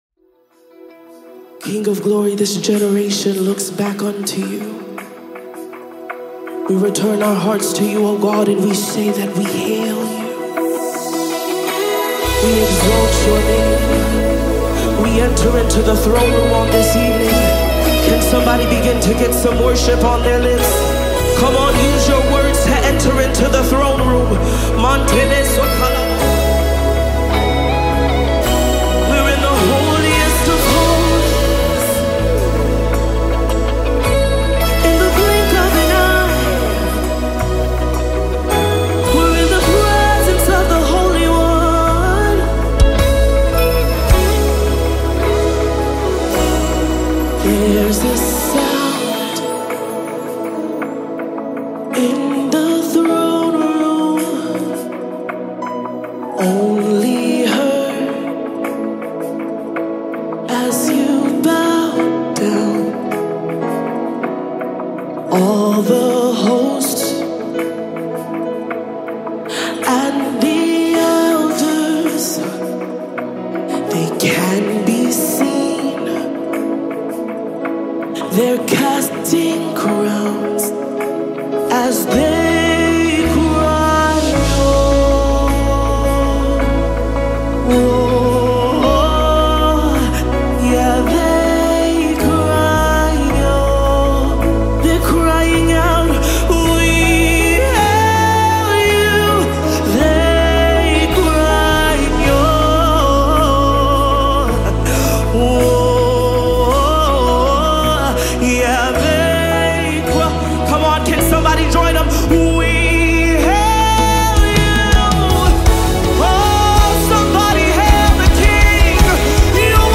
soul-stirring anthem of adoration and praise
With its uplifting melody and stirring lyrics
rich vocals